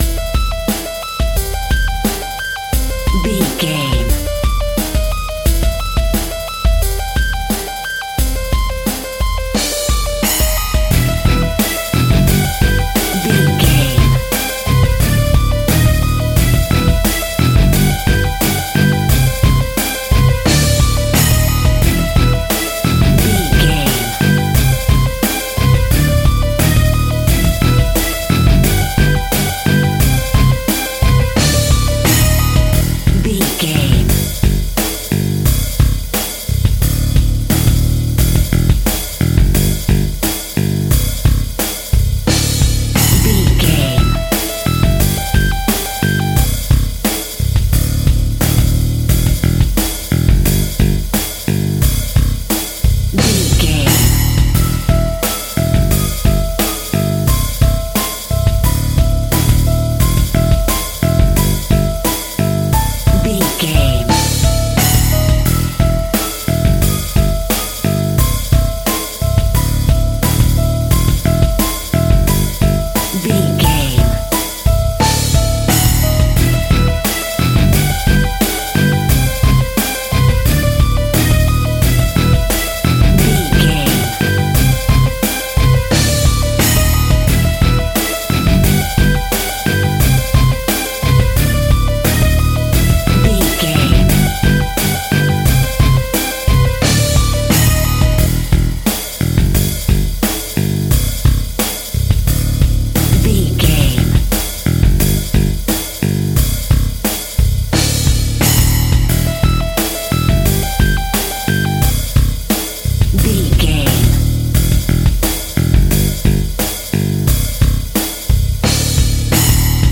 Rocking Halloween.
Aeolian/Minor
scary
ominous
dark
eerie
electric guitar
drums
bass guitar
synthesizer
pads